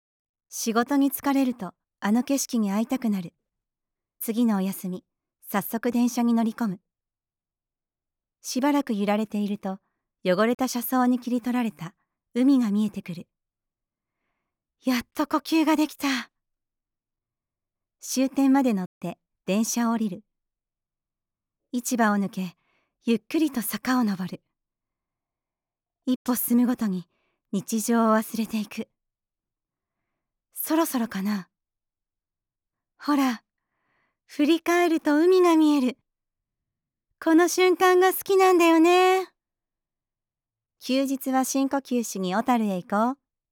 しっかり内容を理解し、印象的かつ聞きやすい声で聴き手に伝わるナレーションをお届けします。
セリフとナレーション